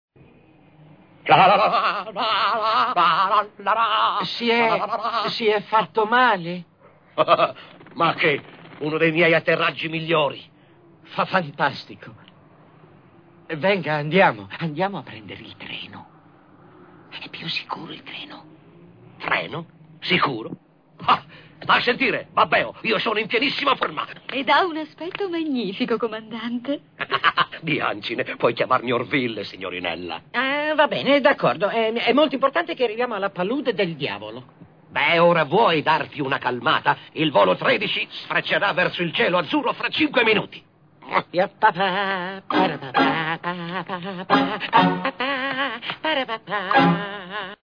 voce di Silvio Spaccesi nel film d'animazione "Le avventure di Bianca e Bernie", in cui doppia Orville.